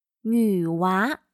女娃/Nǚwá/[En el habla coloquial] chica.